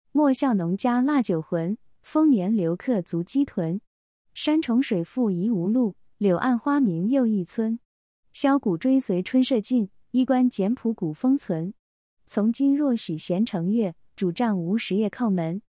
それぞれに中国語の朗読音声も付けておりますの韻を含んだリズム感を聞くことができます。